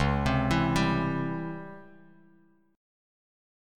DbmM7b5 Chord